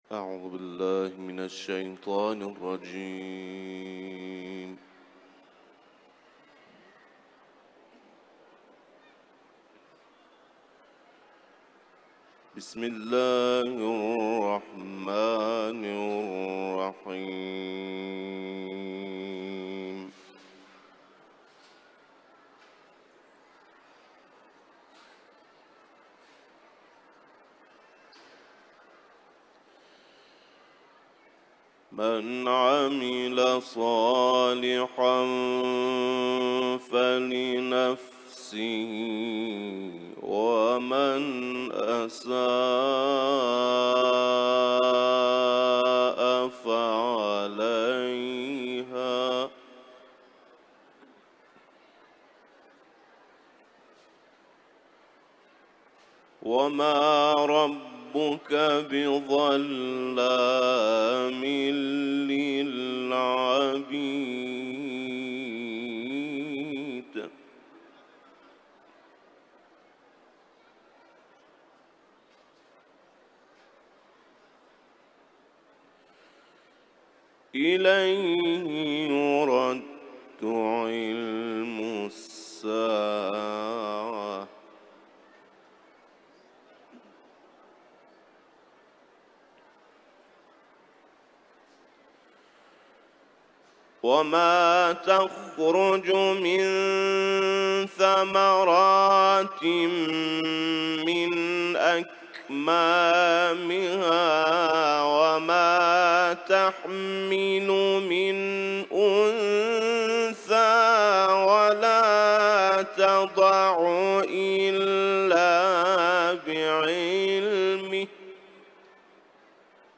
İranlı kâri Kur’an-ı Kerim’den ayetler tilavet etti